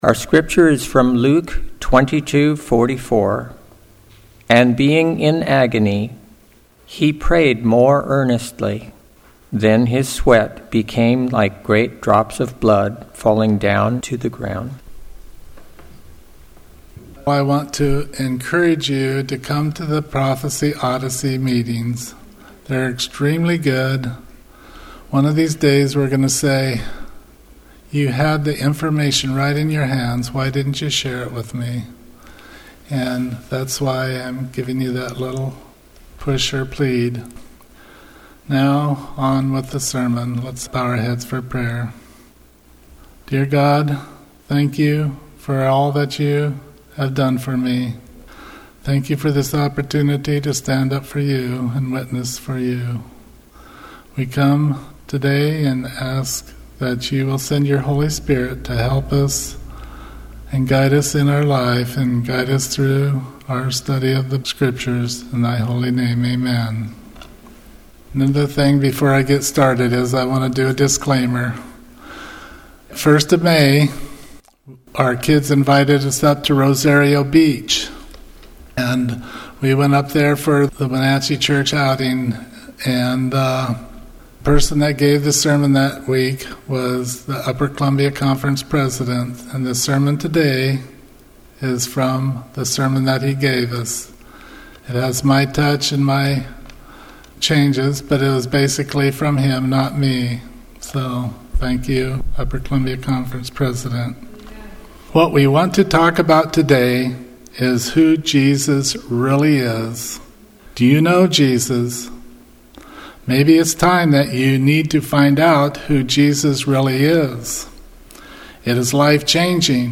Seventh-day Adventist Church, Sutherlin Oregon
Sermons and Talks 2024